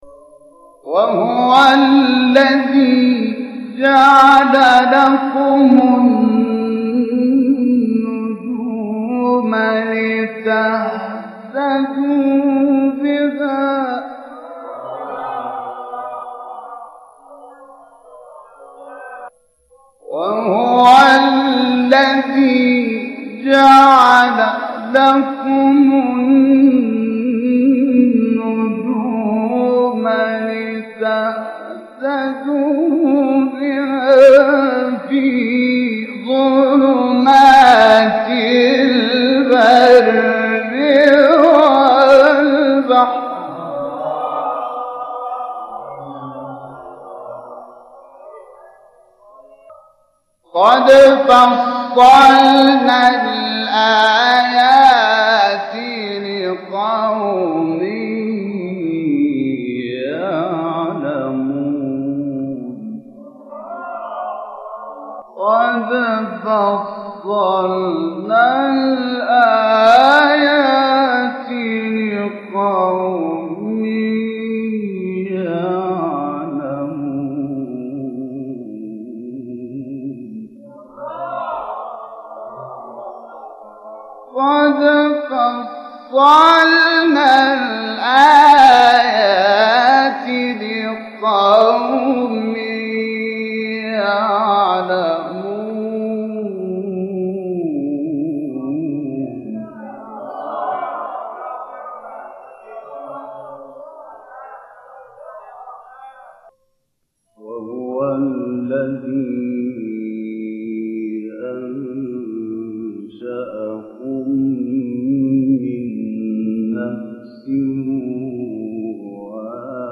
مقام العجم (تلاوة ) الانعام - لحفظ الملف في مجلد خاص اضغط بالزر الأيمن هنا ثم اختر (حفظ الهدف باسم - Save Target As) واختر المكان المناسب